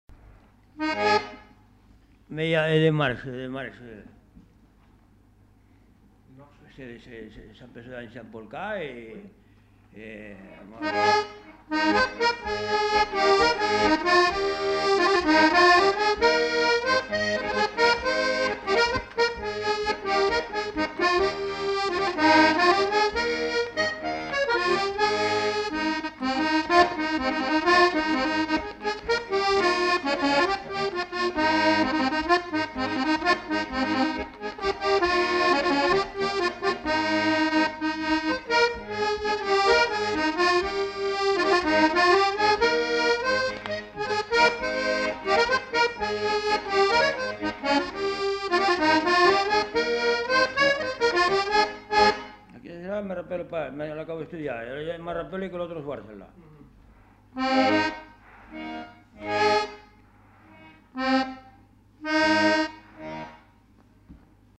Répertoire d'airs à danser du Savès joué à l'accordéon diatonique
enquêtes sonores
Polka piquée